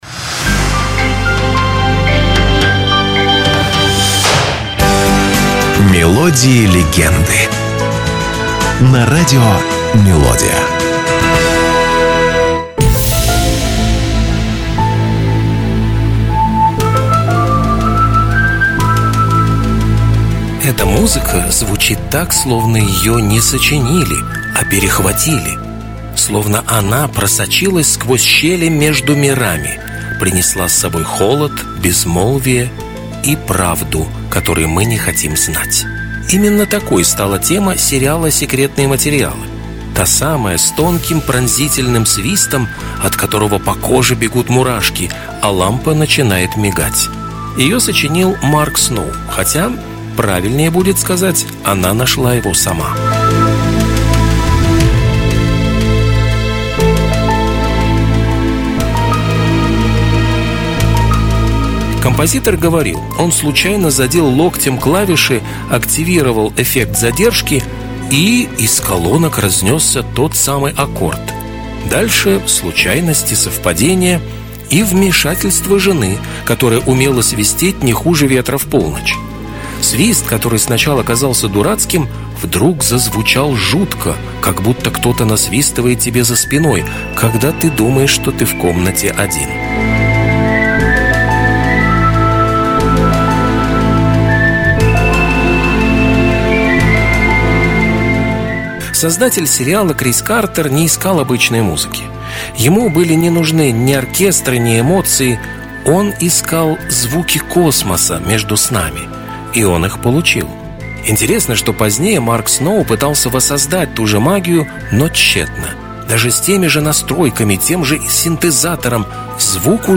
Это короткие музыкальные рассказы с душевным настроением, атмосферой ностальгии и лёгкой интригой. Вы услышите песни, которые звучали десятилетиями и, возможно, впервые узнаете, что стоит за этими знакомыми нотами.